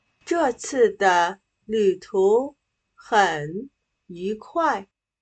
这次的旅途很愉有快。/Zhè cì de lǚtú hěn yúkuài./Esta viaje fue un placer.